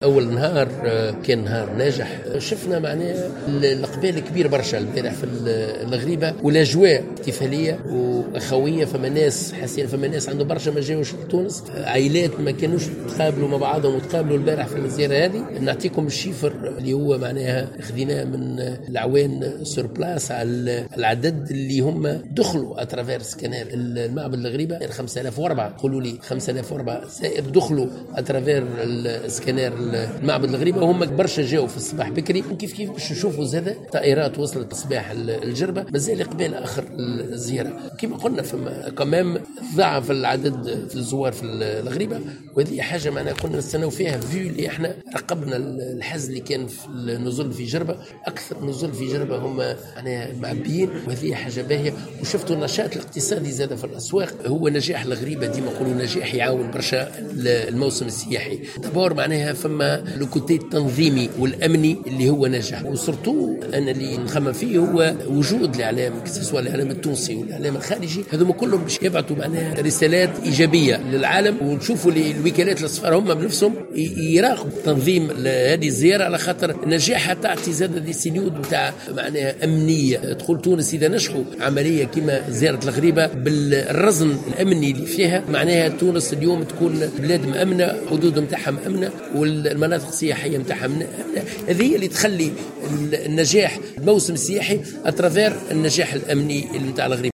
وأضاف الطرابلسي في ندوة صحفية عقدها، الخميس، مع وسائل إعلام وطنية وأجنبية، أن الزيارة حققت بذلك نجاحا هاما أمنيا وتنظيميا وعلى مستوى عدد الزوّار، الذي تضاعف مقارنة بزوار 2018.